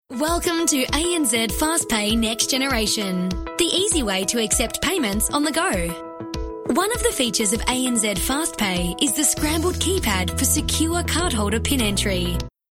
female voice artist with RMK Voices Melbourne